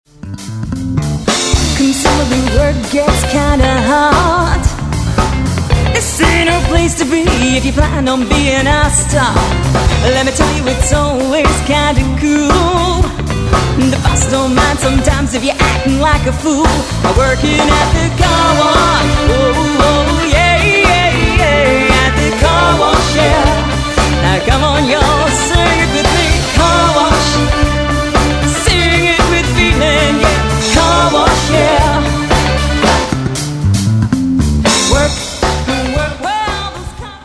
Wedding band soundclips